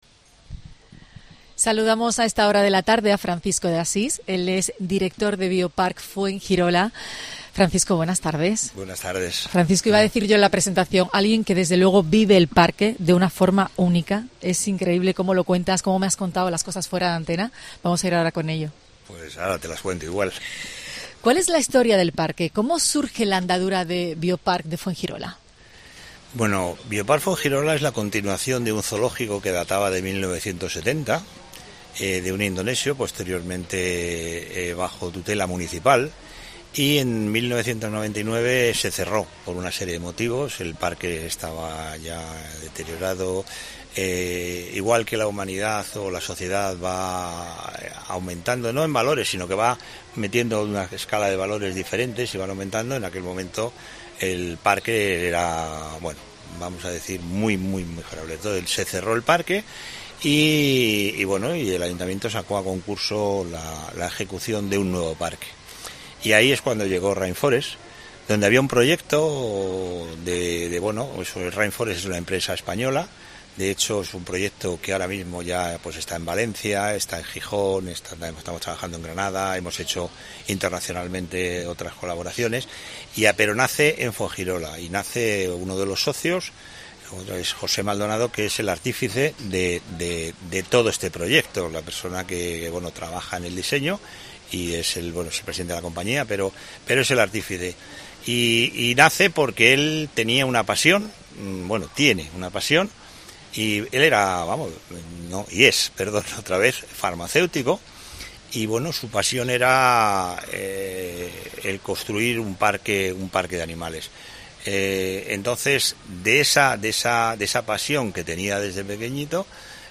El equipo de COPE Andalucía sigue recorriendo kikómetros para llegar a un parque único en Europa: Bioparc Funegirola.